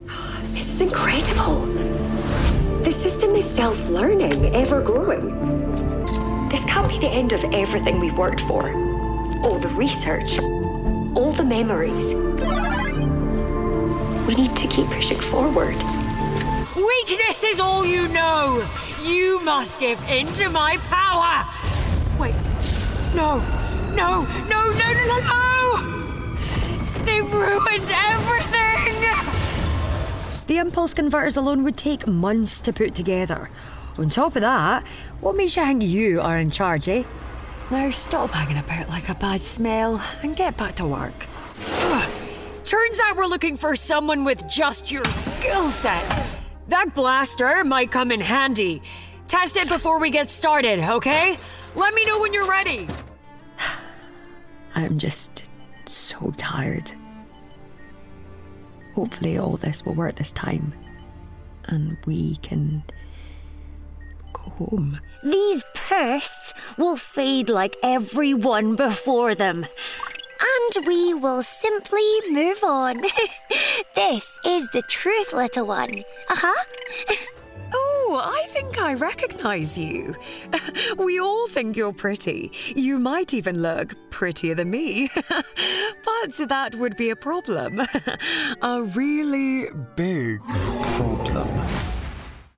Engels (Schotland)
Veelzijdig, Vriendelijk, Warm, Commercieel, Zakelijk